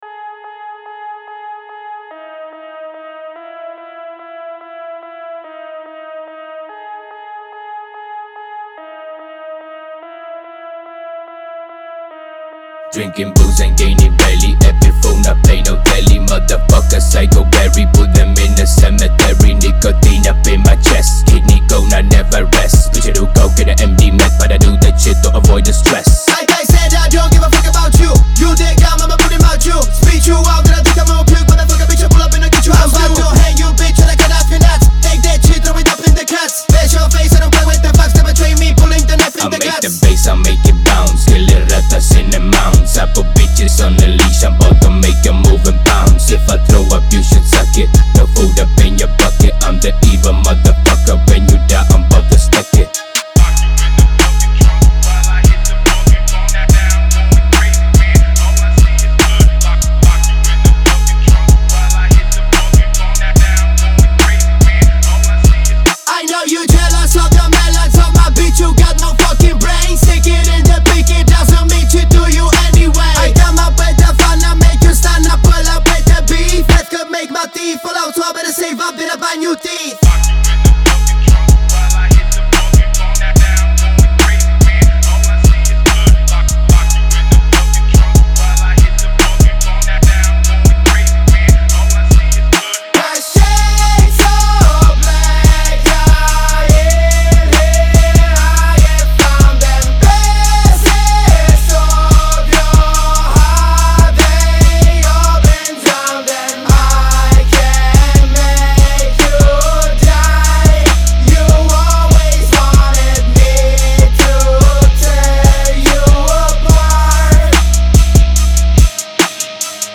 Фонк музыка